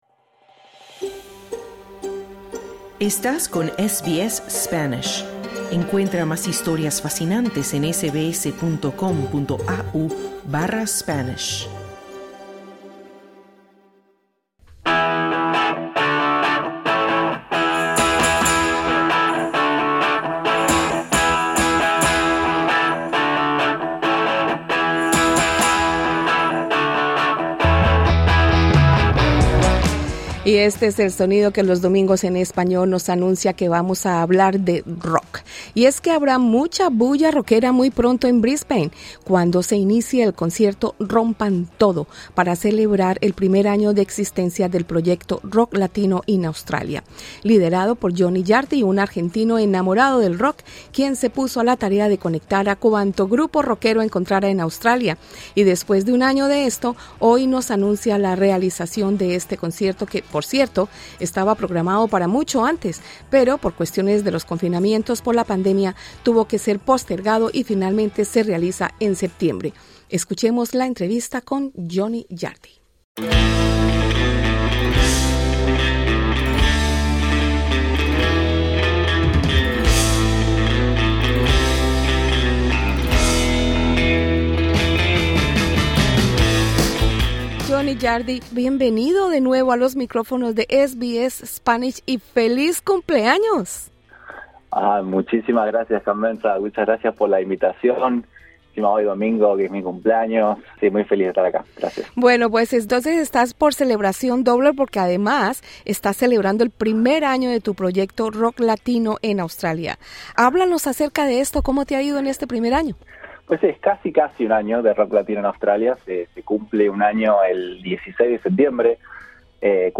SBS en español